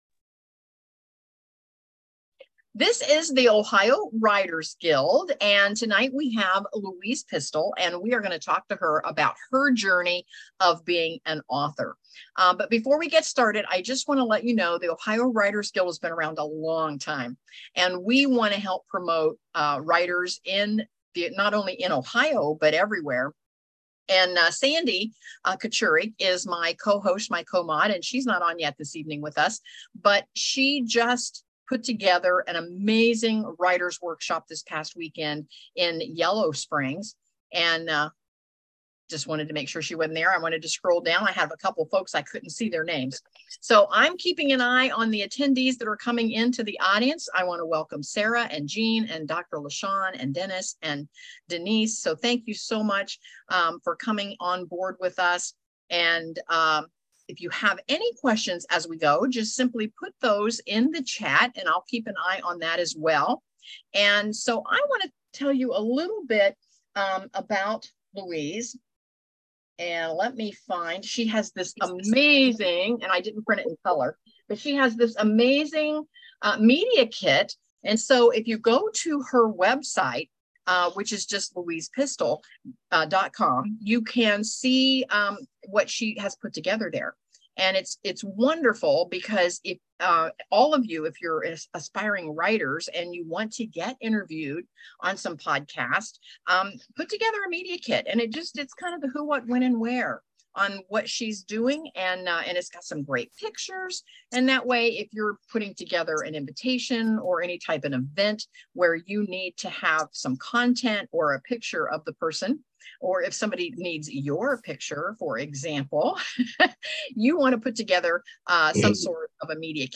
Ohio Writers' Guild | Interview